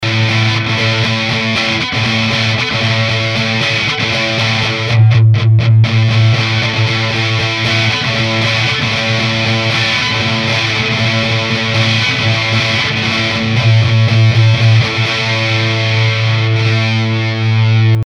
На гитарах неплохо, здесь правда сигнал разогнан на 6дб лимитером. Записывалось в пред за 3000 руб. peratronika mab-2013 (ina103). Осторожно в конце щелчок.